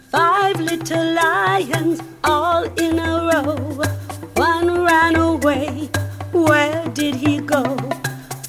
Genre: Children's Music.